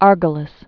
(ärgə-lĭs)